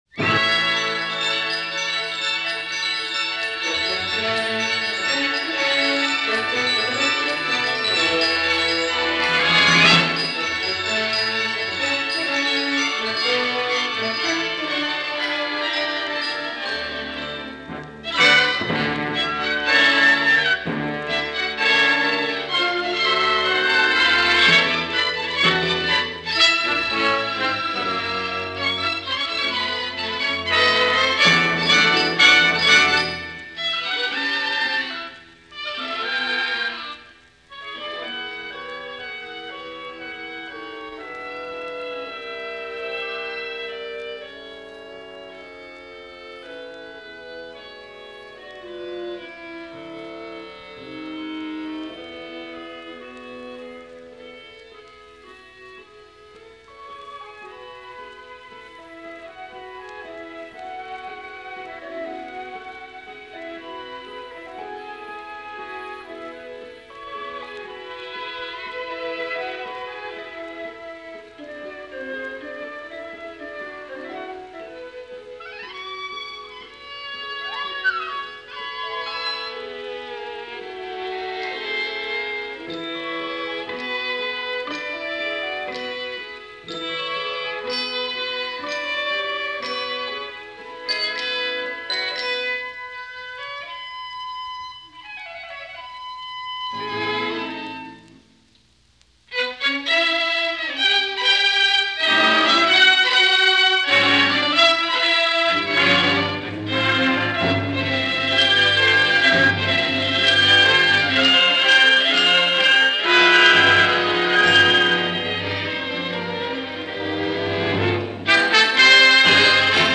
is transcribed for Orchestra
a broadcast performance